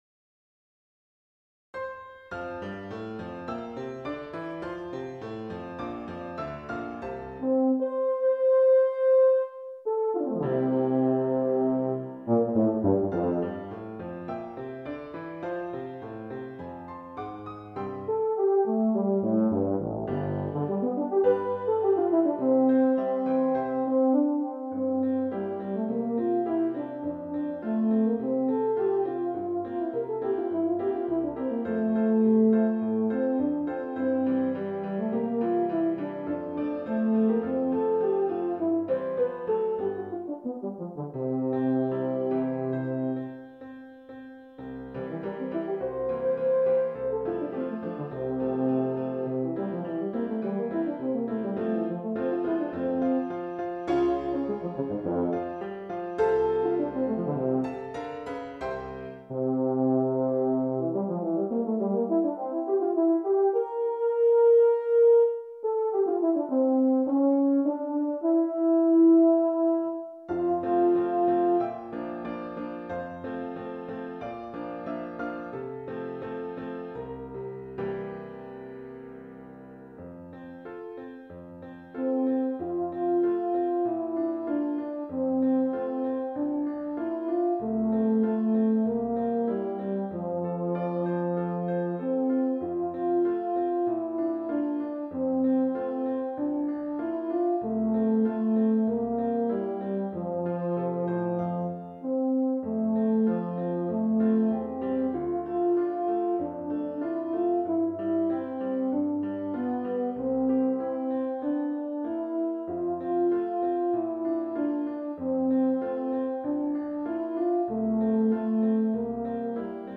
Voicing: EuTbn Solo